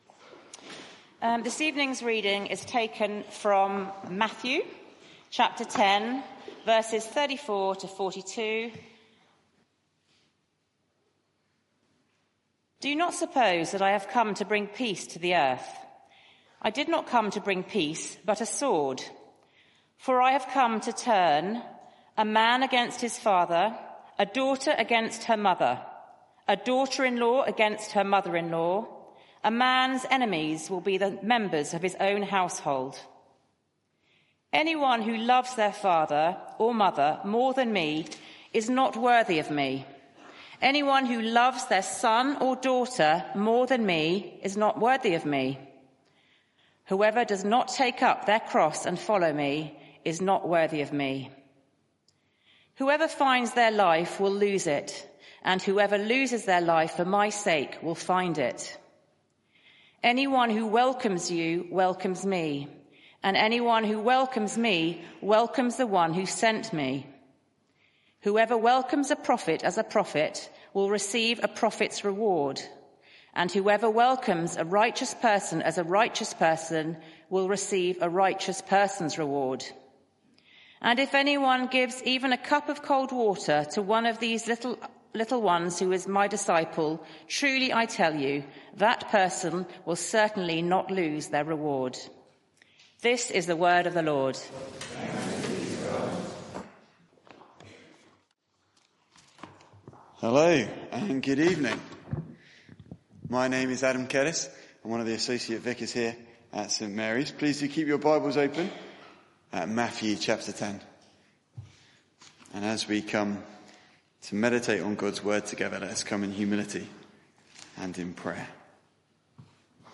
Media for 6:30pm Service on Sun 09th Jun 2024
Passage: Matthew 10:34-42 Series: Jesus confronts the world Theme: Jesus' divisiveness Sermon (audio)